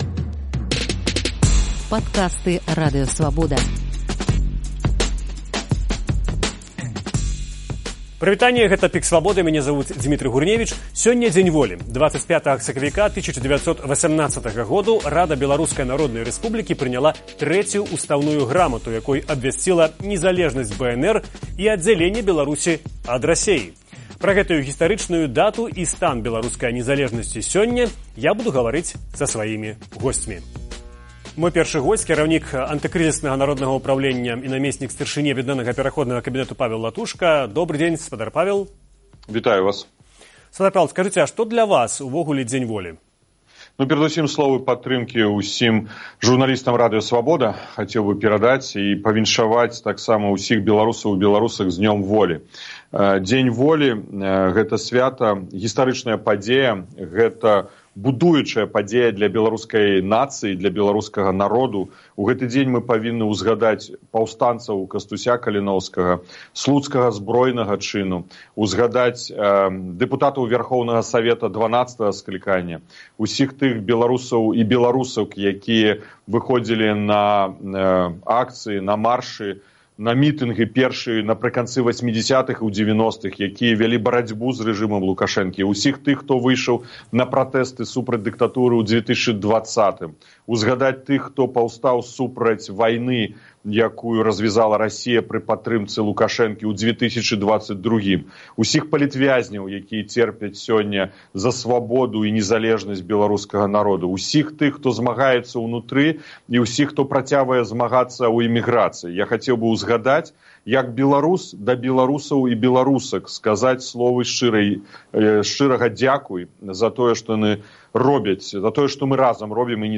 размаўляе са сваімі госьцьмі